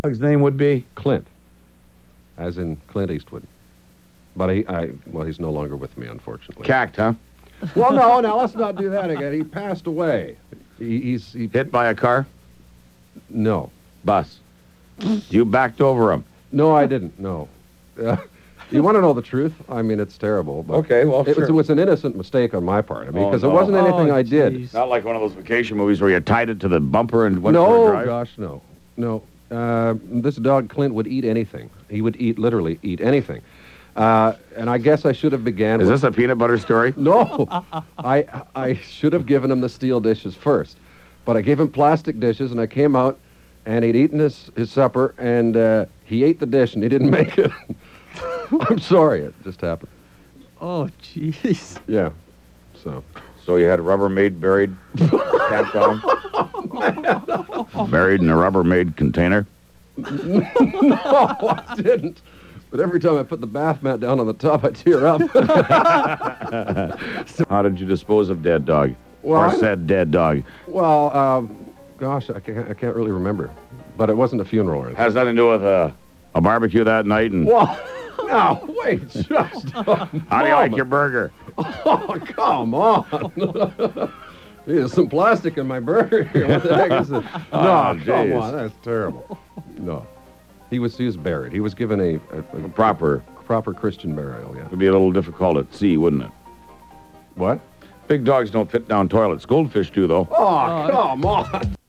This is the entire collection of my favourite clips that I kept from the shows I recorded.
I kept the funniest or most interesting banter.